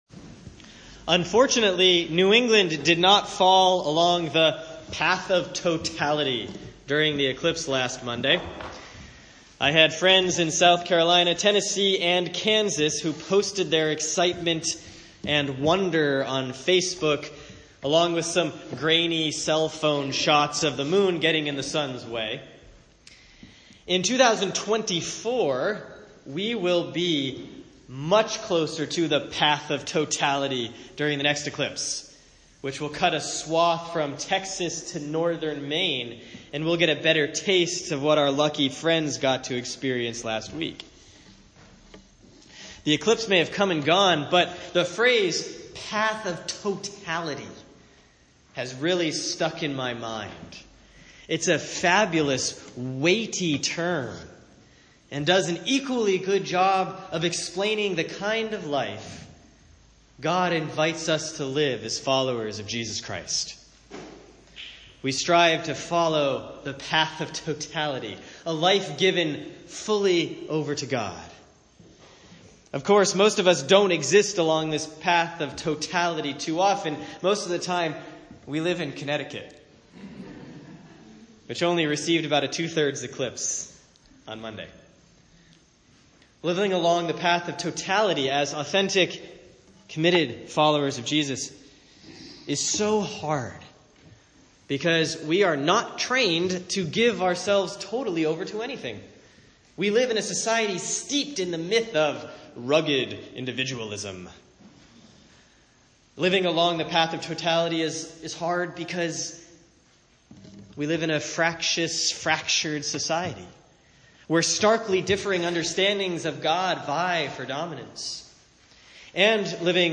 Sermon for Sunday, August 27, 2017 || Proper 16A || Romans 12:1-9